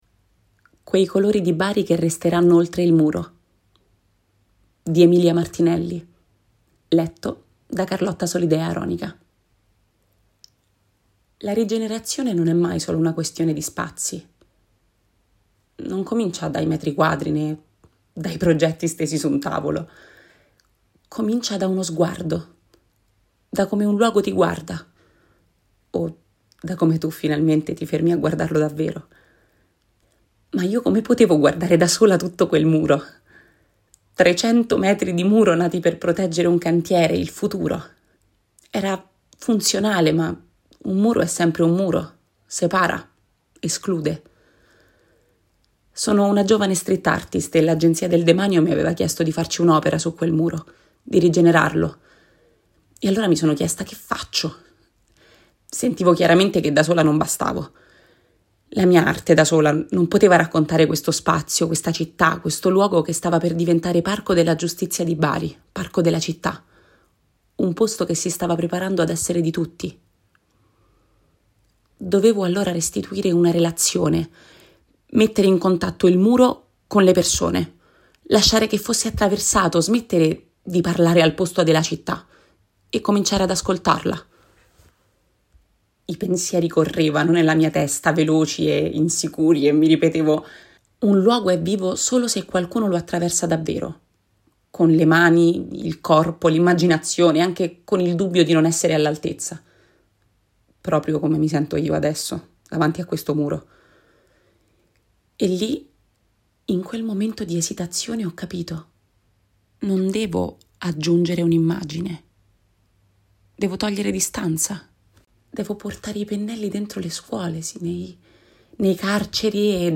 Il racconto